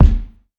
CDK Simple Kick.wav